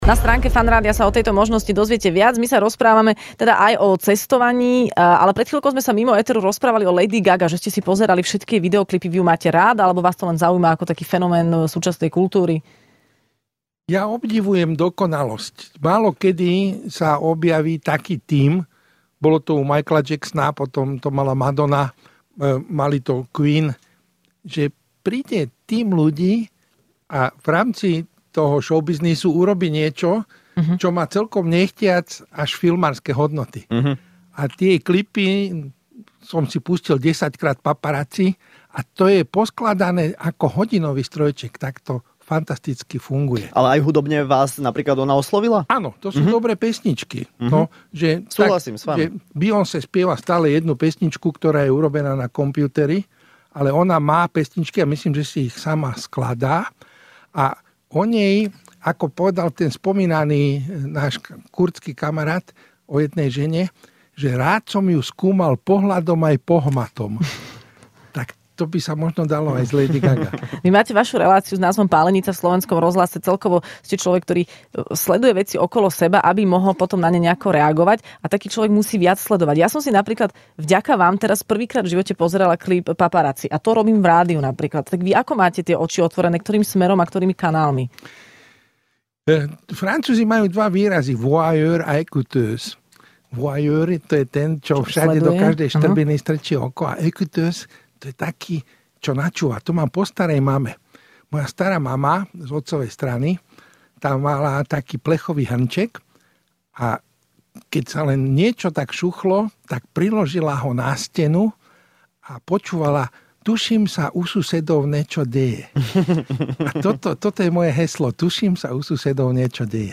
Hosťom v Rannej šou bol cestovateľ a spisovateľ Boris Filan, ktorý dostal aj ocenenie